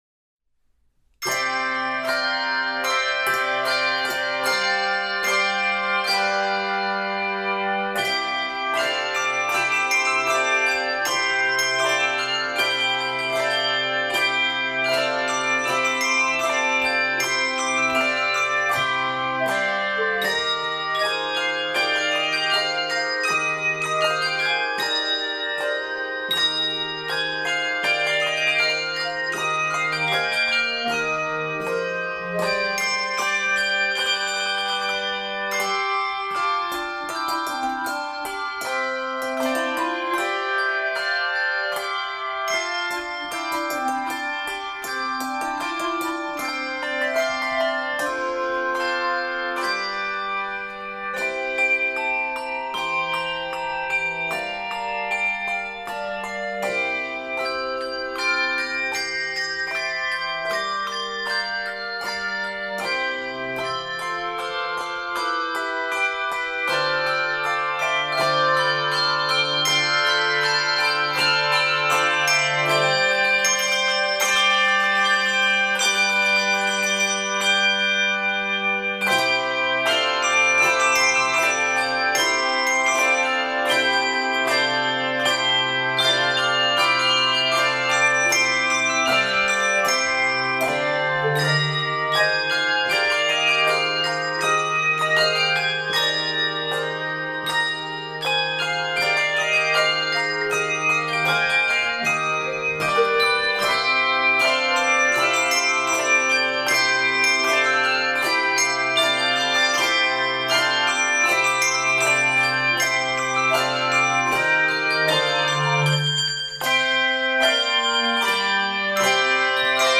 handbells
Key of C Major. 83 measures.